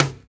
Index of /90_sSampleCDs/Roland - Rhythm Section/TOM_Real Toms 1/TOM_Dry Toms 1
TOM STONE1ER.wav